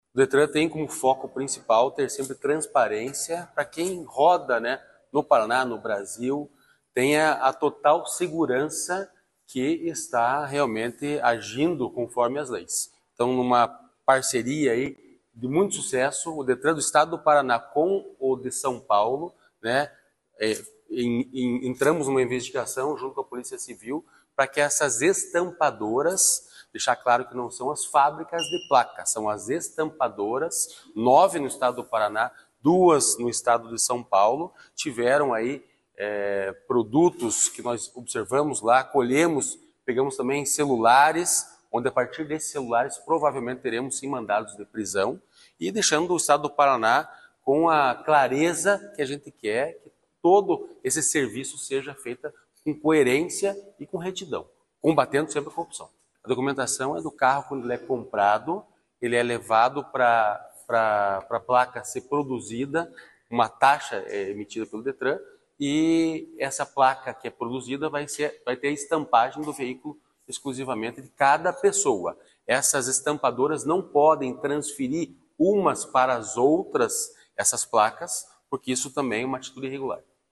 Sonora do diretor-presidente do Detran-PR, Santin Roveda, sobre a operação contra empresas de emplacamento ligadas a fraudes veiculares